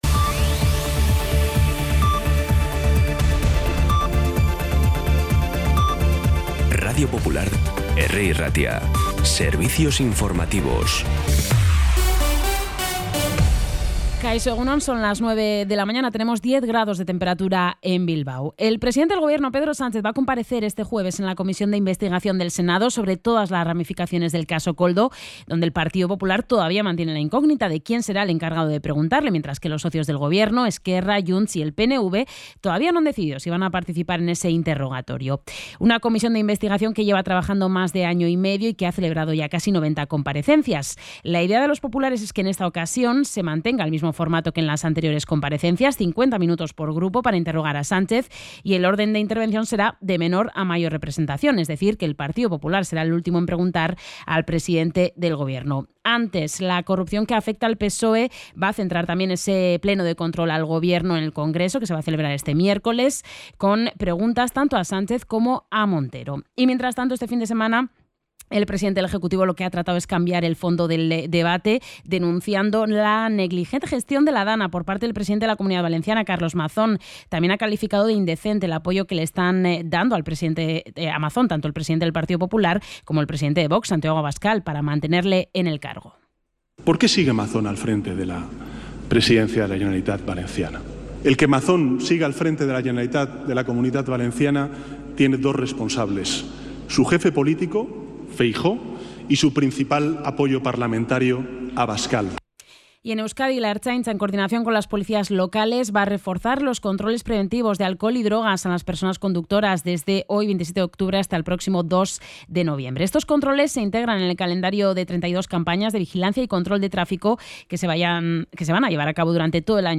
La redacción de informativos de Radio Popular – Herri Irratia trabaja durante todo el día para ofrecerte el resumen informativo más compacto.
Los titulares actualizados con las voces del día. Bilbao, Bizkaia, comarcas, política, sociedad, cultura, sucesos, información de servicio público.